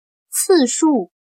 次数 \ cìshù \frecuencia